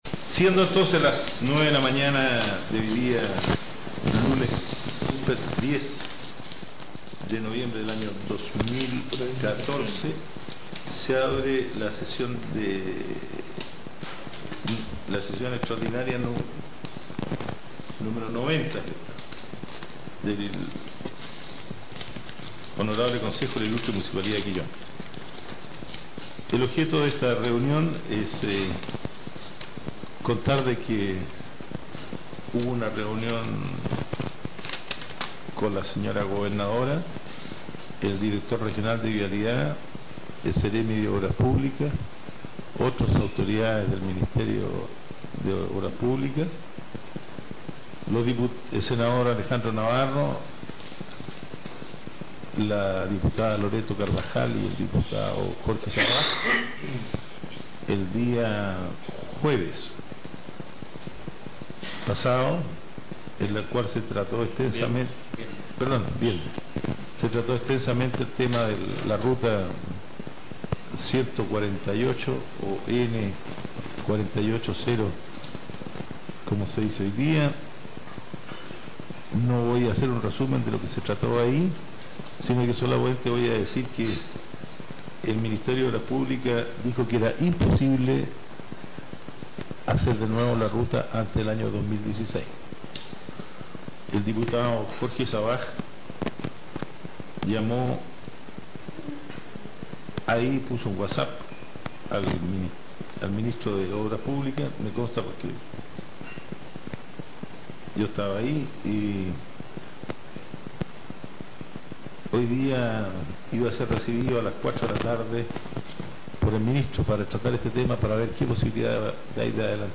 Audio del Concejo 2014